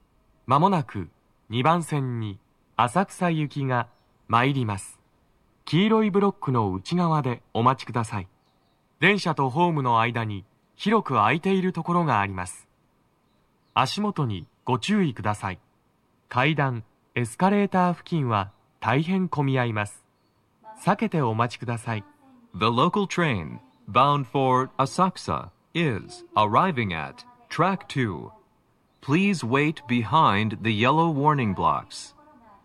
スピーカー種類 TOA天井型
🎵接近放送
鳴動は、やや遅めです。